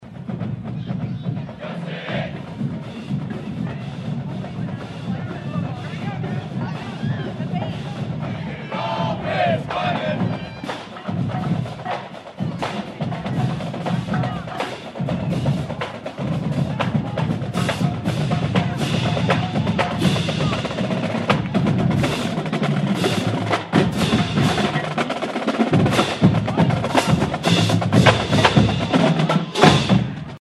Sounds-of-the-Parade.mp3